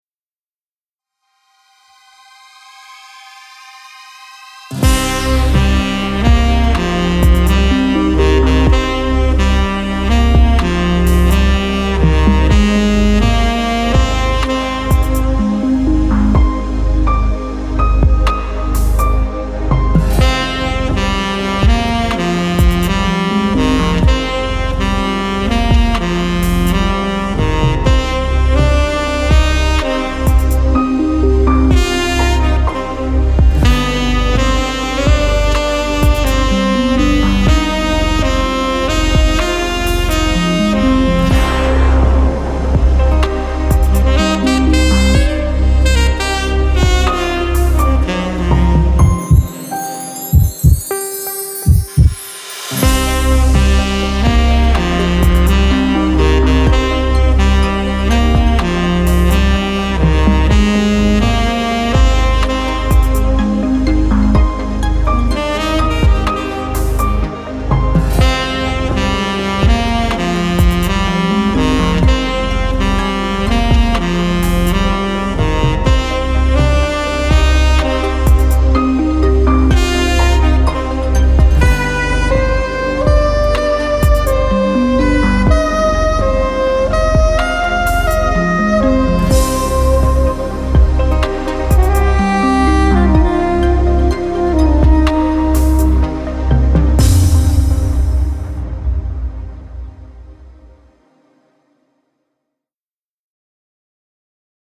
Other info: Pop